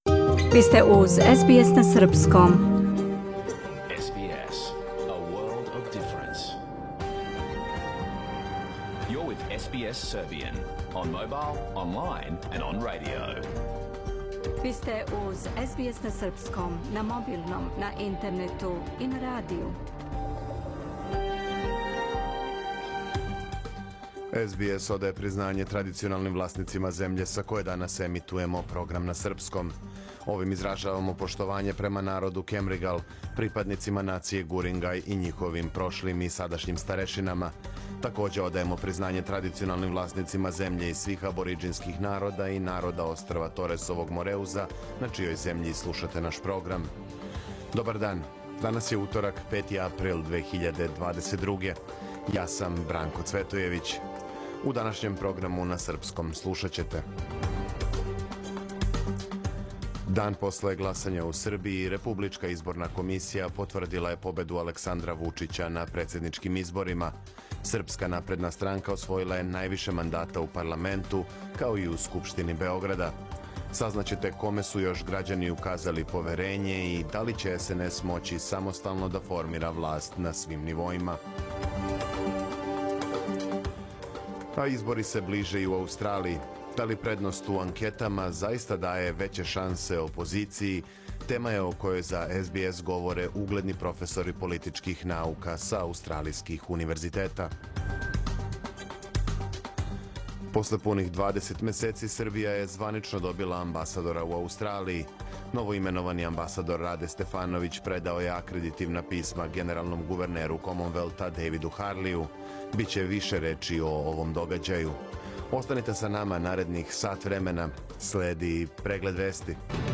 Програм емитован уживо 5. априла 2022. године